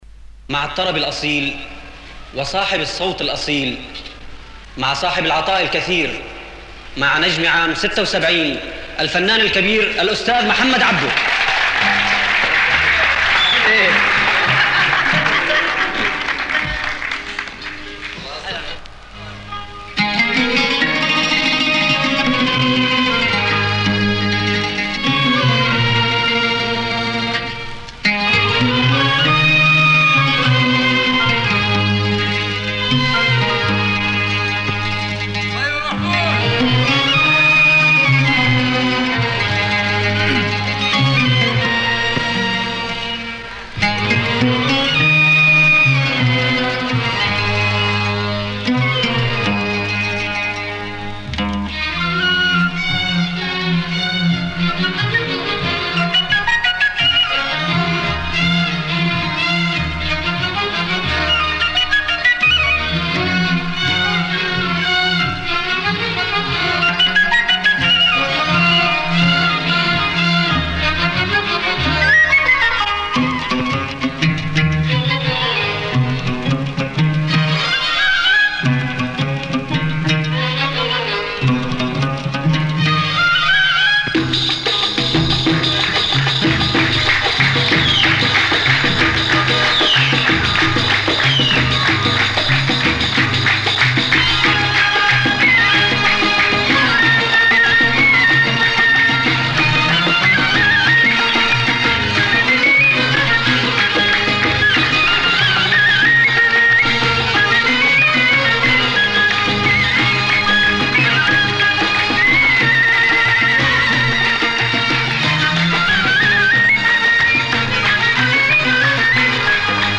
Crazy sounds, great condition !